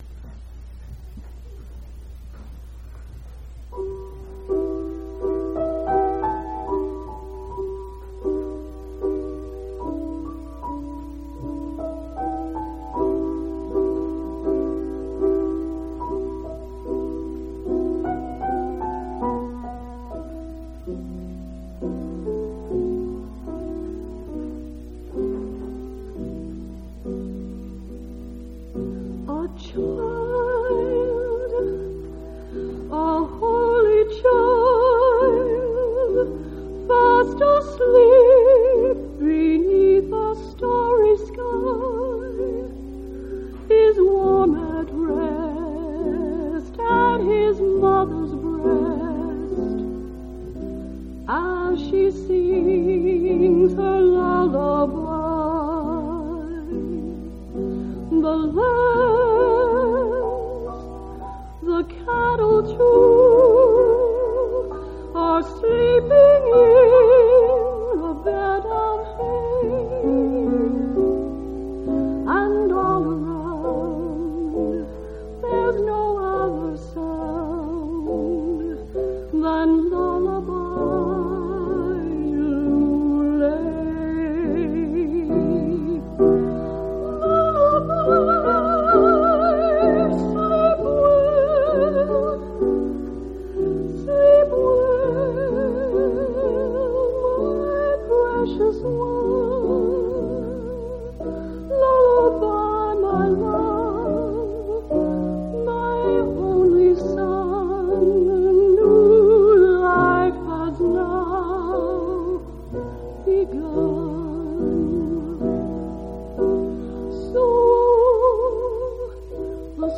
12/15/2002 Location: Temple Lot Local Event